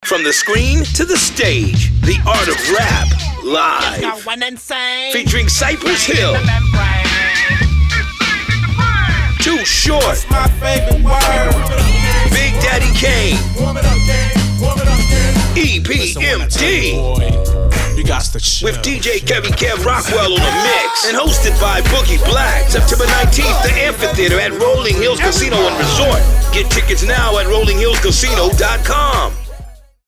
Radio Spot Something From Nothing - Sample Radio 30 TV Spot Media